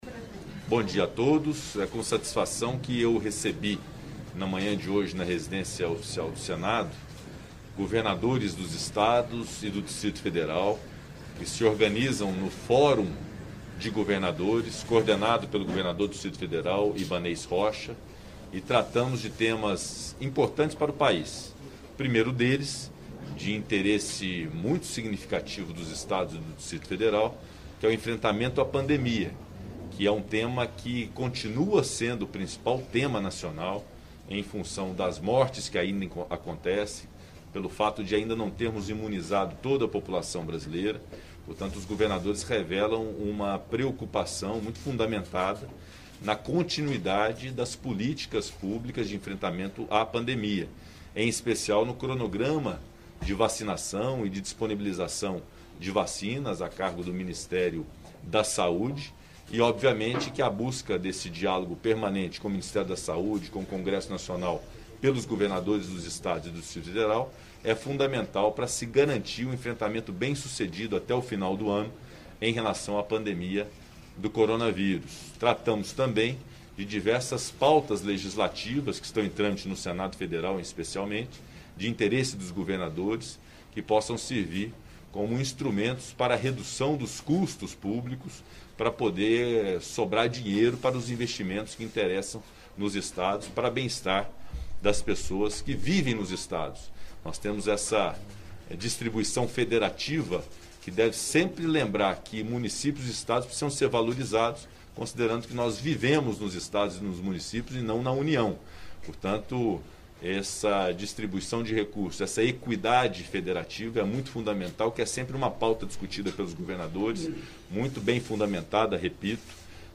Em nome dos governadores, Ibaneis Rocha, do Distrito Federal, disse que é preciso distensionar o país, para criar um ambiente melhor para o desenvolvimento. Ouça as íntegras dos pronunciamentos de Pacheco e de Ibaneis.